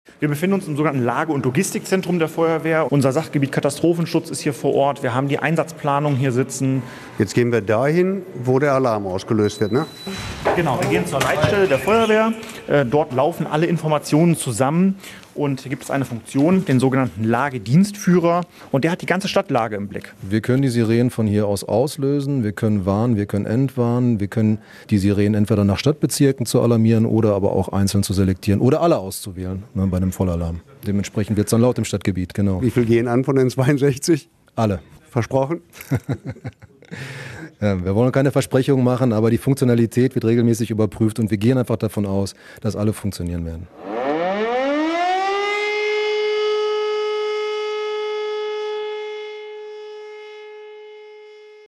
rundgang-leitstelle-feuerwehr-essen.mp3